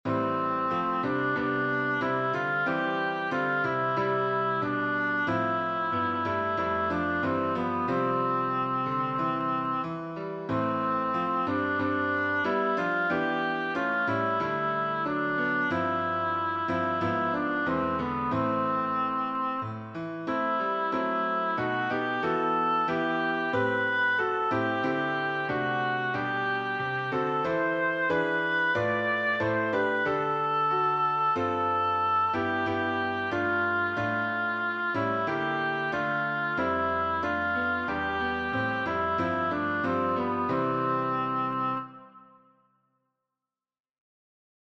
This piano accompaniment is an SATB four-part harmonization.